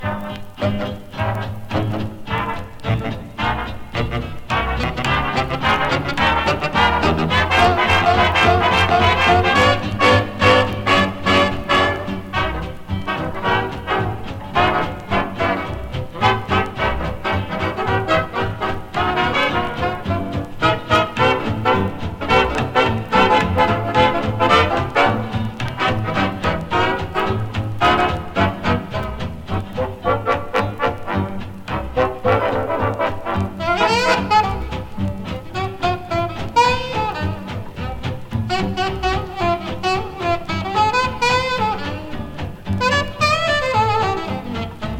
アルトサックス奏者兼バンドリーダー
Jazz, Big Band, Swing　USA　12inchレコード　33rpm　Mono/Stereo